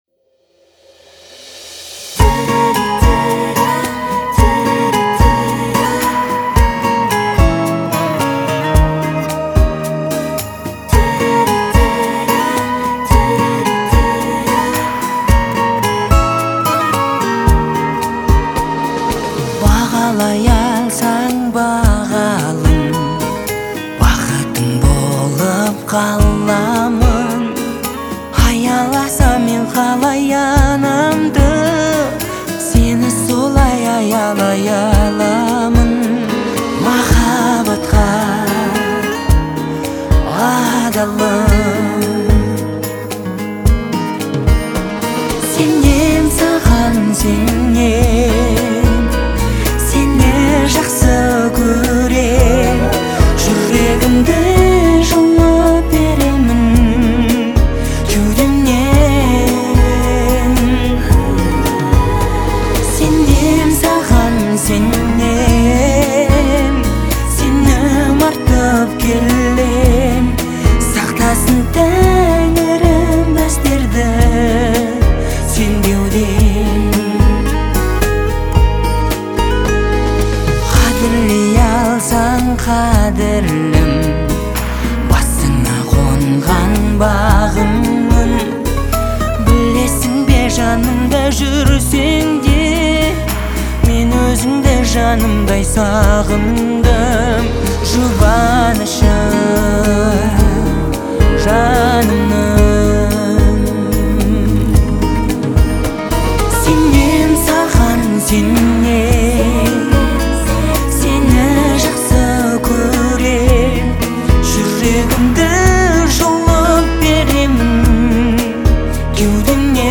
это трогательная песня в жанре казахского попа